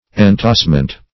Search Result for " entassment" : The Collaborative International Dictionary of English v.0.48: Entassment \En*tass"ment\, n. [F. entassement, fr. entasser to heap up.] A heap; accumulation.